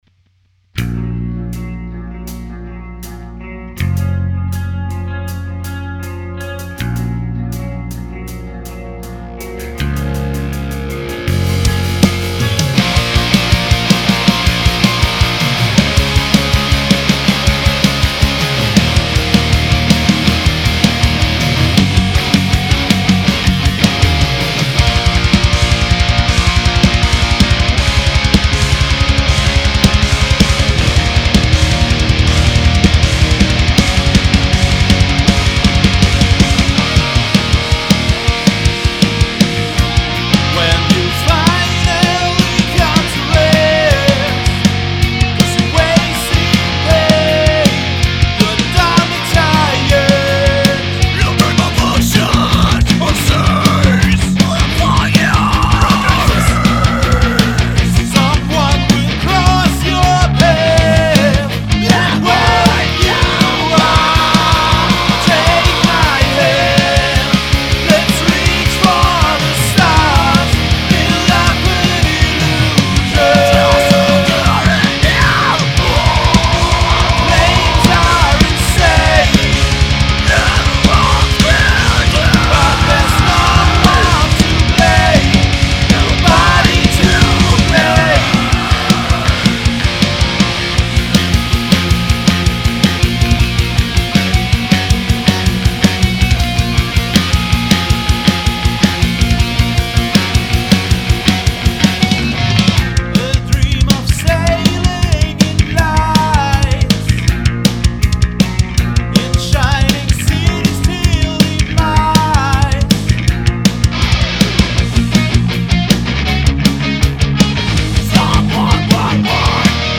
Punkrock / Post-Hardcore Song von nem Frischling